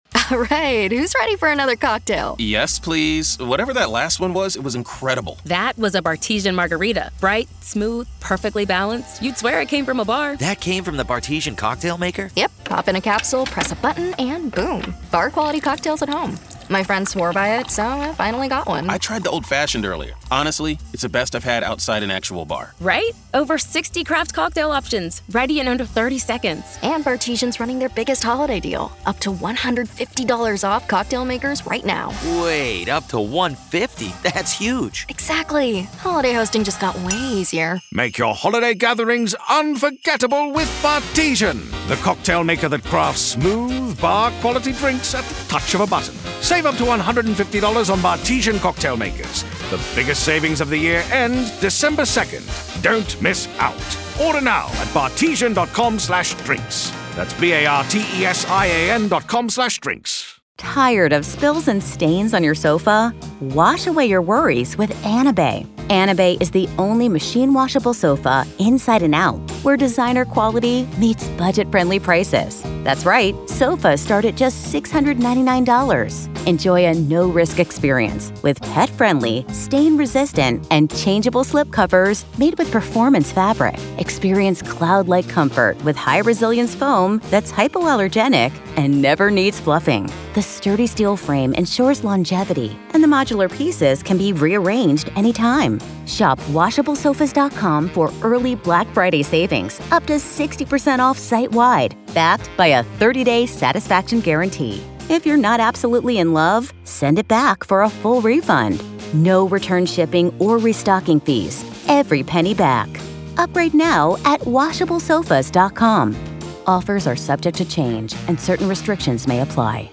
The latest Spanish news headlines in English: 11th April 2024